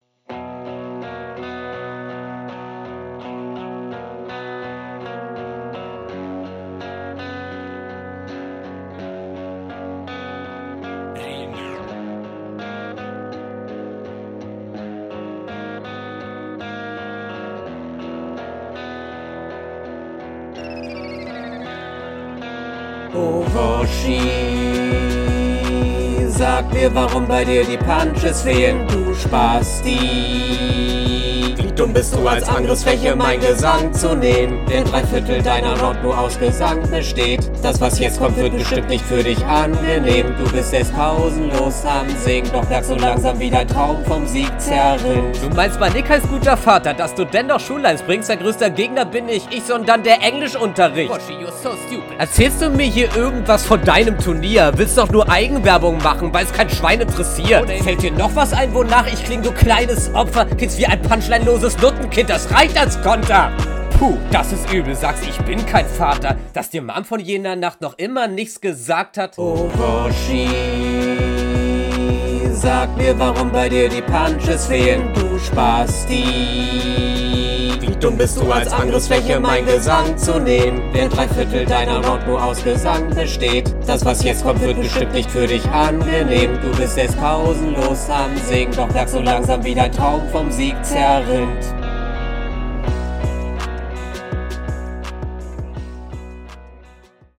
Die doubles sind giga unclean andere noten die null zusammenpassen. Singen lassen wir ma lieber.